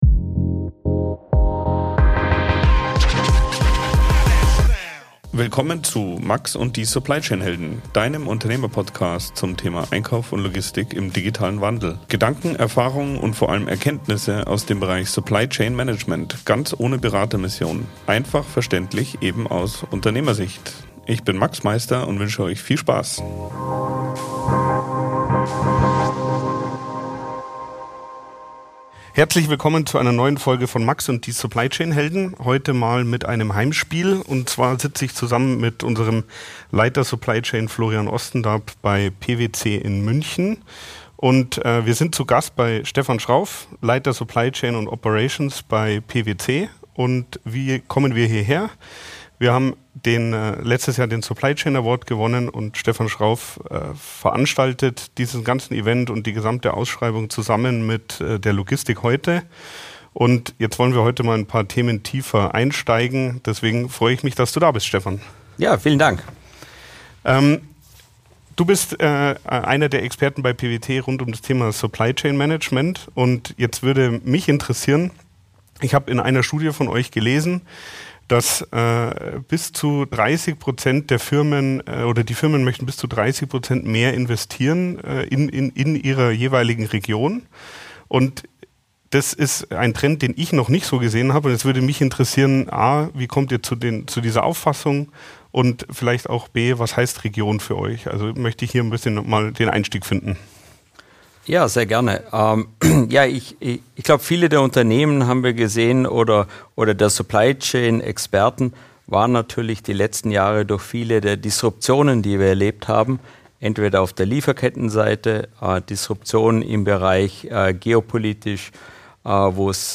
Dein Unternehmer Podcast. Interviews und Erkenntnisse von und mit Profis aus dem Bereich Supply Chain Management – ganz ohne Berater-Mission.